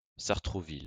լսել). saʁtʁuvil), քաղաք և համայնք Ֆրանսիայում՝ Իլ դը Ֆրանս երկրամասի Իվլին դեպարտամենտում։ Փարիզի հյուսիսարևմտյան արվարձանն է և ընկած է երկրի մայրաքաղաքի կենտրոնից՝ զրոյական կետից մոտավորապես 17 կիլոմետր հեռավորության վրա, Սեն գետի ափին։ Զբաղեցնում է շուրջ 8,5 քառակուսի կիլոմետր տարածք։ 1990 թվականի տվյալներով բնակչության թվաքանակը եղել է50 329[4][5][6][7], 2011 թվականի տվյալներով՝ 51 431[8].